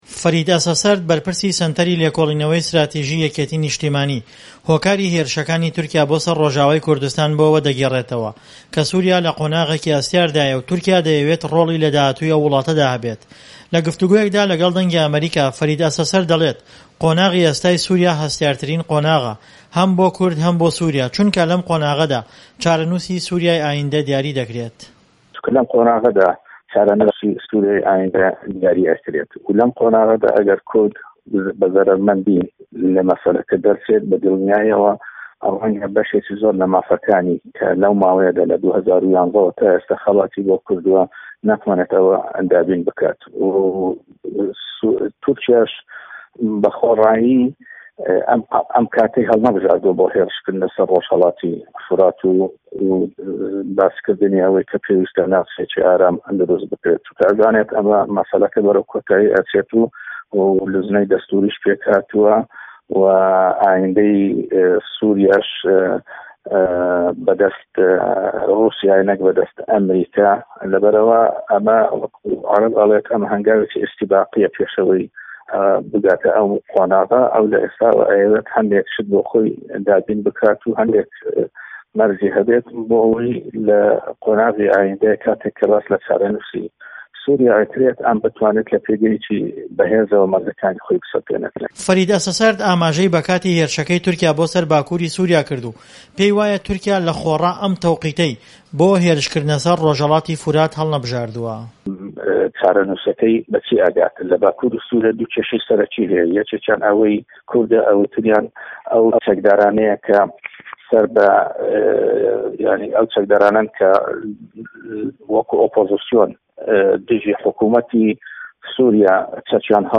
لە گفتووگۆیەکدا لەگەڵ دەنگى ئەمەریکا